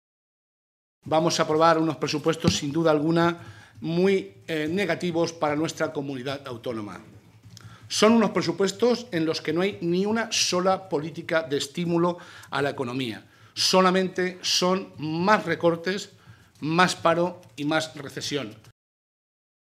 Molina, que ofreció una rueda de prensa momentos antes del inicio del pleno que se desarrollará hoy y mañana en la cámara regional, indicó que lo más grave de las cuentas para el próximo año es que “son totalmente injustas, antisociales y vuelven a atacar a los más débiles”.